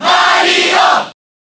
File:Mario Cheer Japanese SSBB.ogg
Mario_Cheer_Japanese_SSBB.ogg